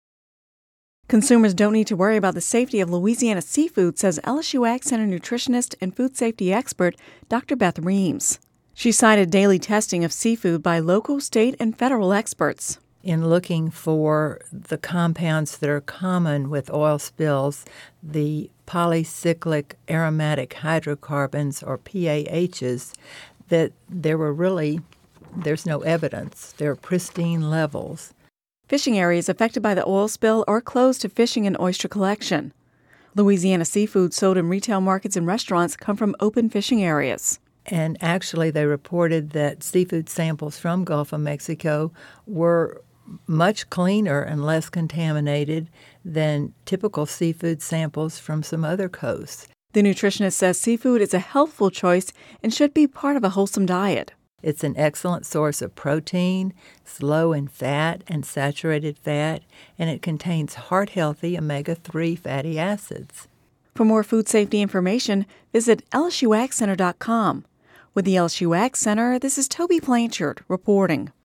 (Radio News 07/05/10)